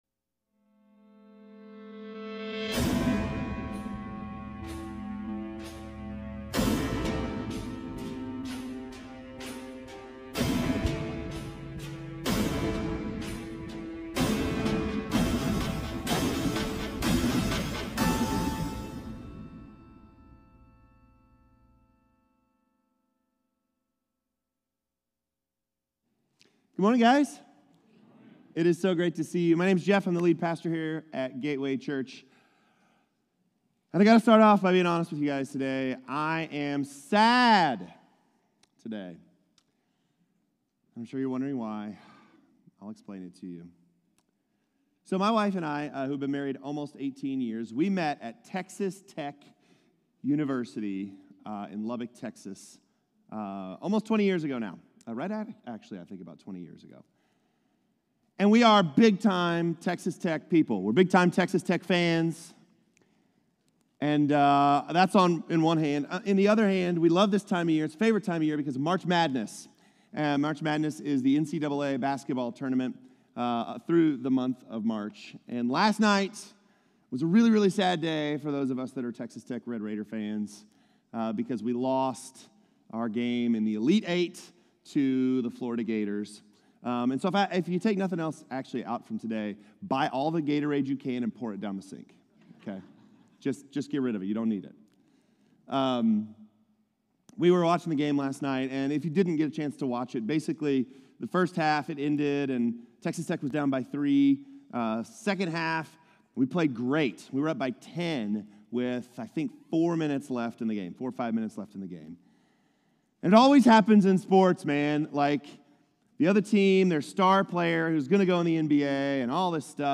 Satan-is-Defeated-Sermon-3.30.25.m4a